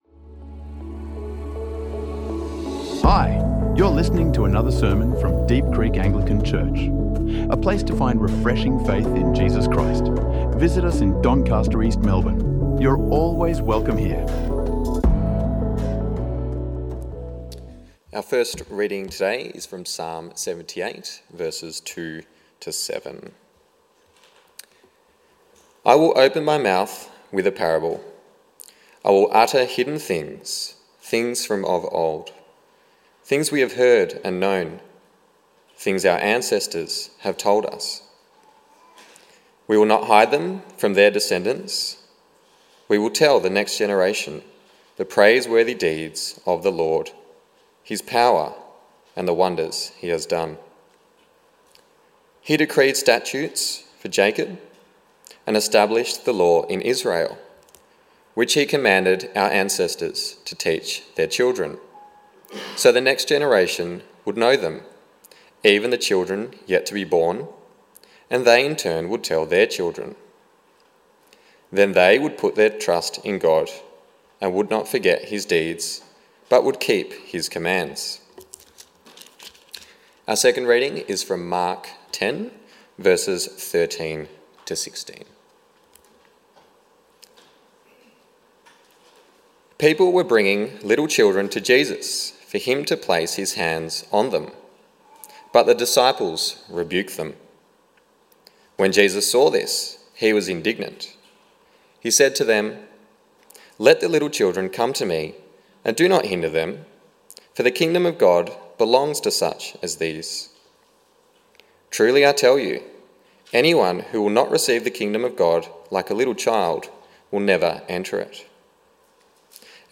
Explore how Jesus champions children and young people in this sermon on Mark 10 and Psalm 78. Discover the value of a listening, intergenerational faith community.